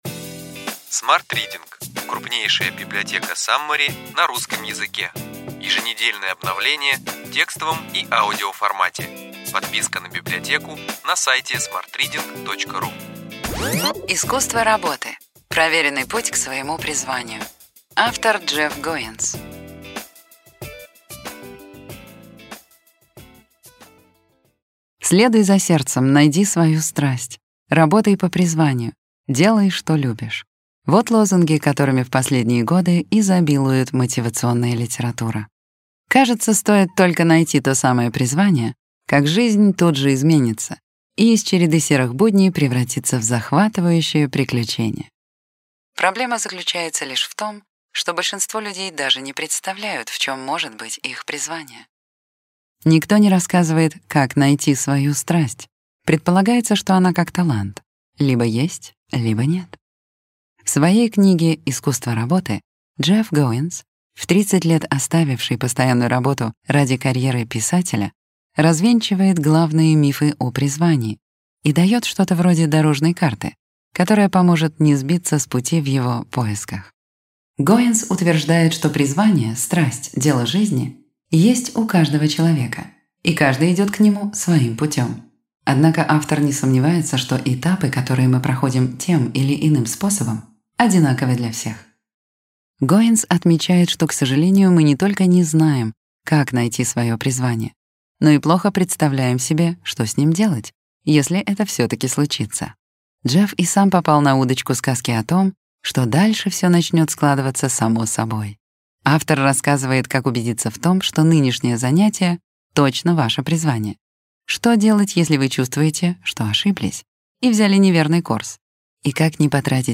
Аудиокнига Ключевые идеи книги: Искусство работы. Проверенный путь к своему призванию.